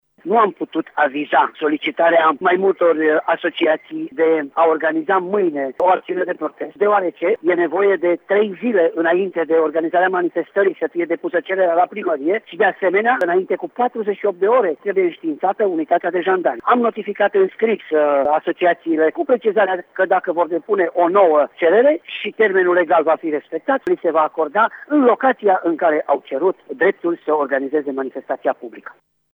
Care sunt motivele aflăm de la șeful Poliției Locale Tîrgu-Mureș, Valentin Bretfelean: